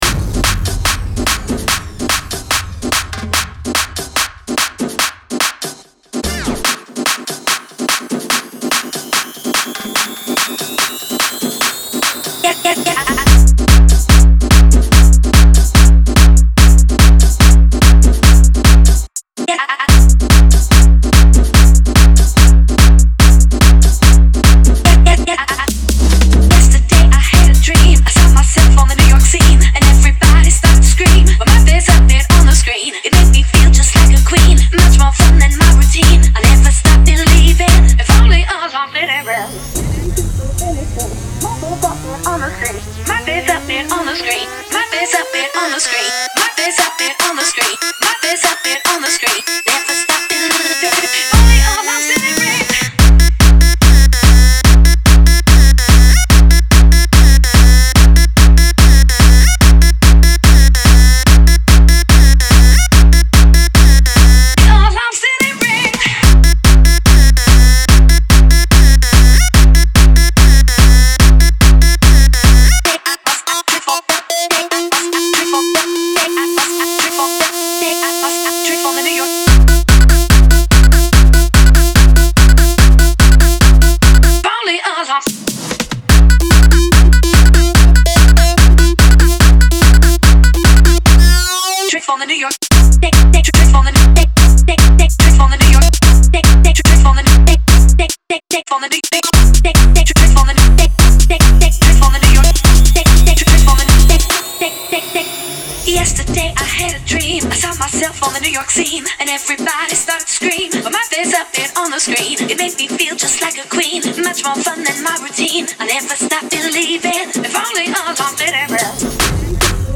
Género: Ogard House.